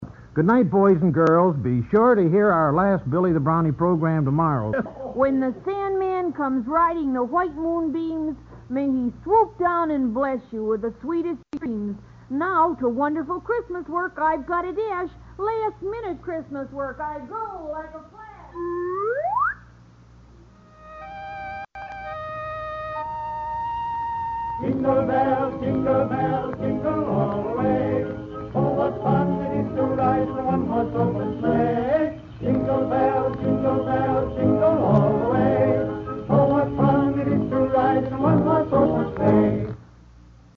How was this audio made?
In Billie's final 1955 broadcast he makes reference to "Sandman", oddly enough this was his new identity in East Germany the following year.